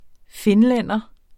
Udtale [ ˈfenˌlεnˀʌ ]